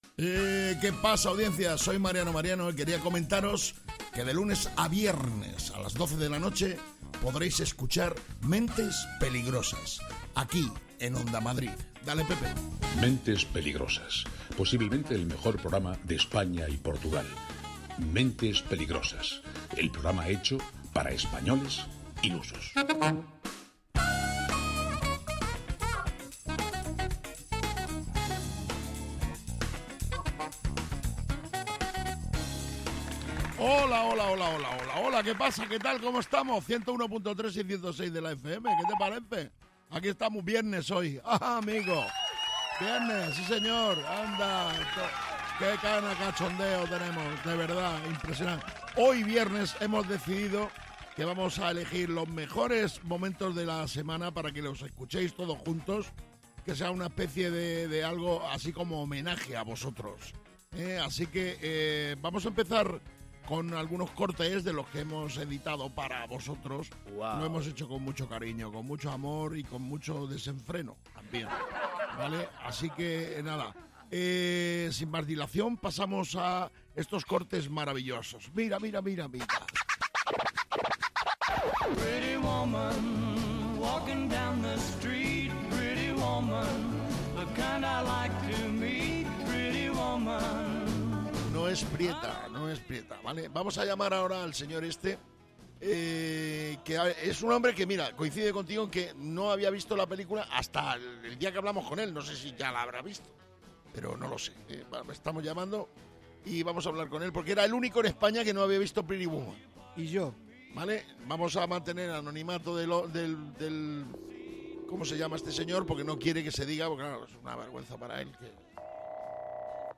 Mentes Peligrosas es humor, y quizás os preguntaréis, ¿y de qué tipo de humor es?